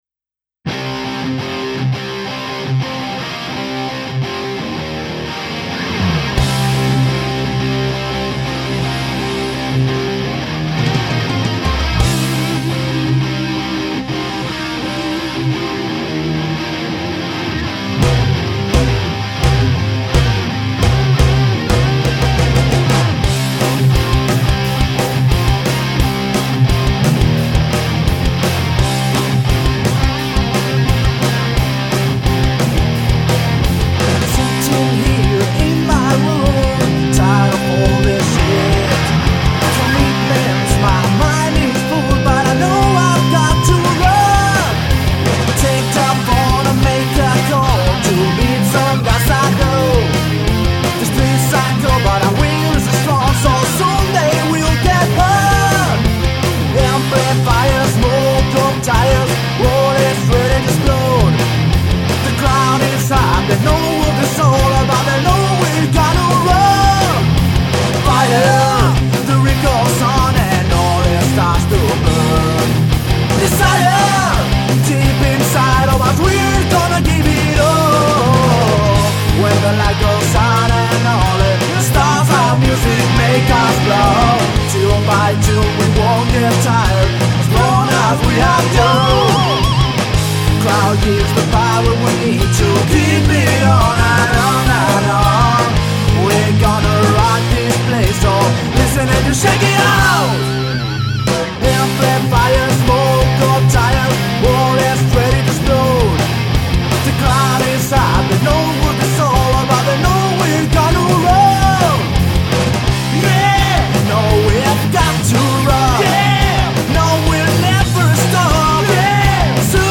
joven banda de Rock
guitarra
batería
bajo